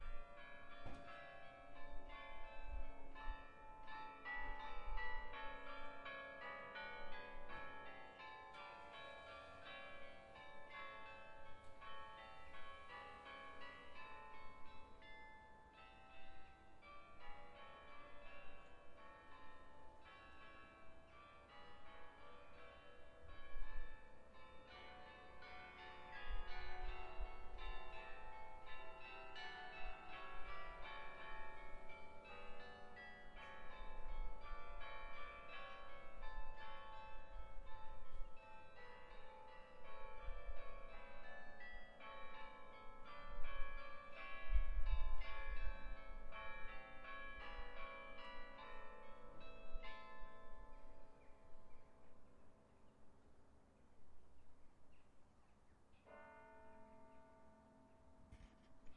Church Clock Strikes 6
描述：The church bell strikes 6 oclock
标签： bells cathedral church Strikes clock bell churchbell ringing
声道立体声